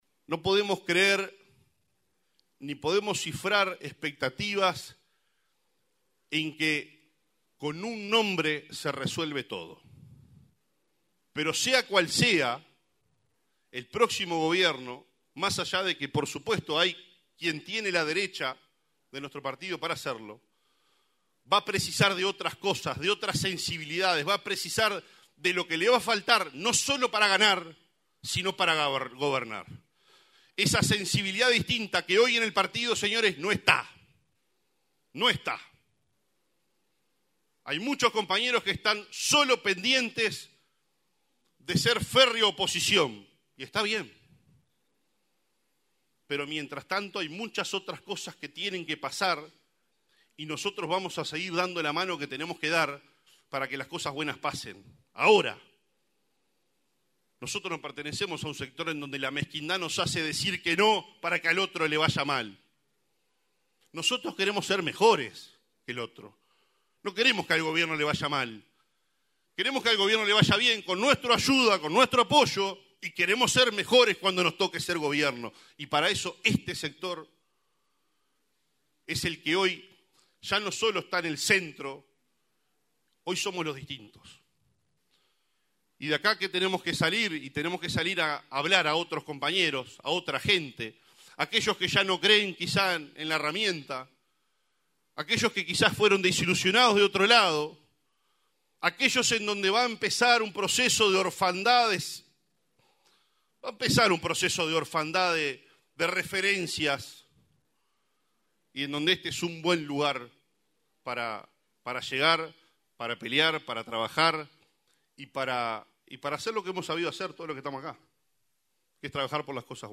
El encuentro tuvo lugar en la Sociedad Criolla La Querencia, donde se destacó la importancia de la jornada para fortalecer al sector y al Partido Nacional en clave de futuro.
En el cierre, el intendente de Paysandú y presidente del Congreso de Intendentes, Nicolás Olivera, llamó a “enamorarse de ideas” y a consolidar un proyecto político que trascienda nombres propios, con énfasis en el trabajo de cercanía y en la Ley de Presupuesto como herramienta transformadora.